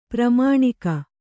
♪ pramāṇika